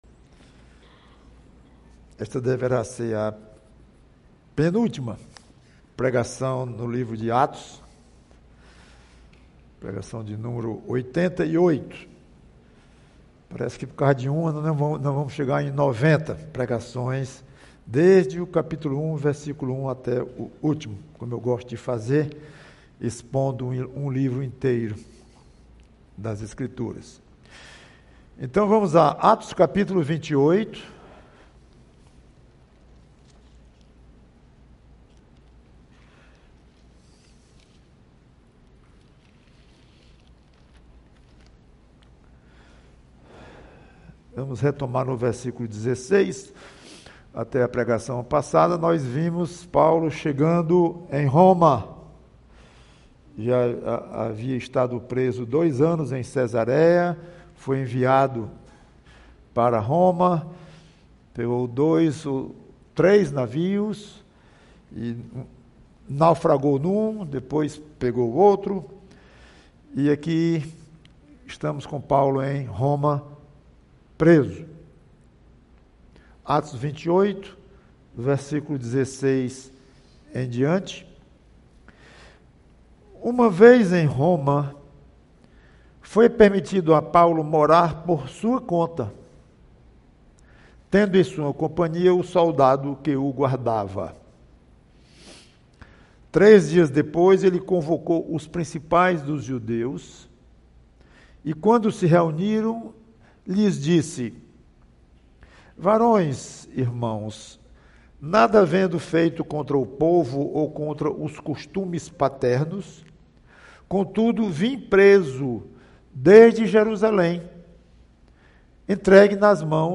PREGAÇÃO A esperança de Israel... e a sua também!